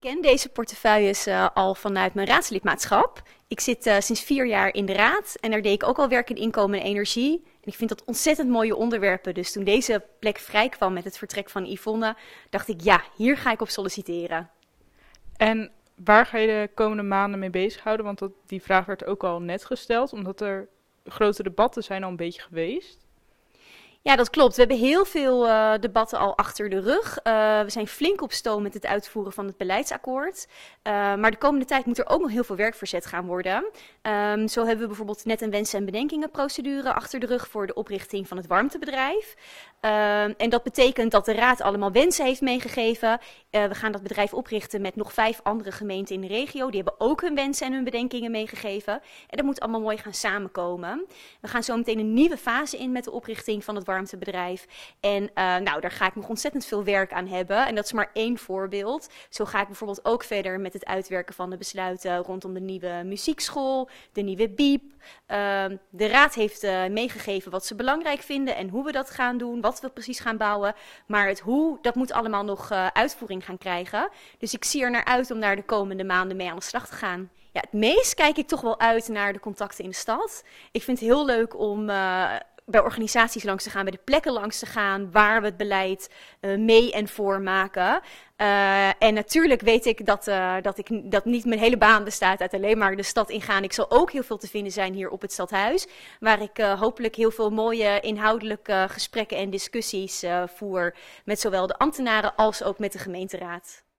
spreekt met nieuw wethouder Prescillia van Noort over haar werkzaamheden de komende maanden